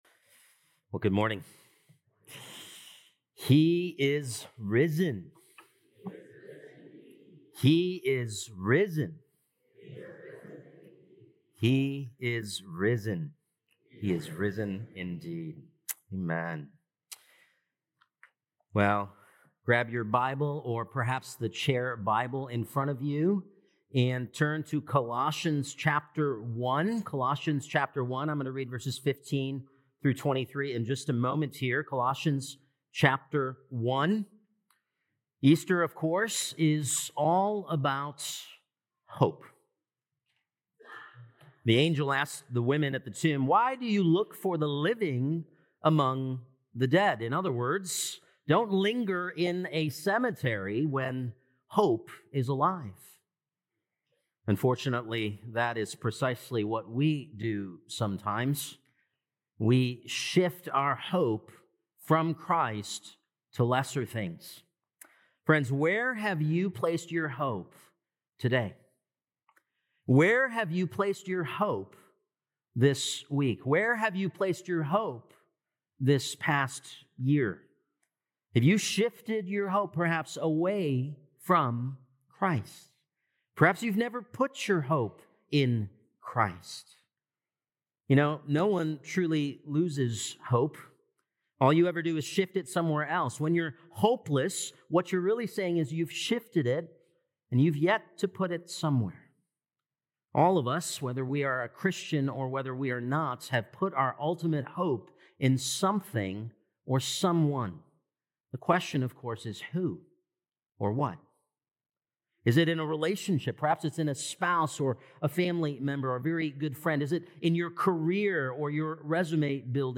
Apr 20th Sermon | Colossians 1:15-23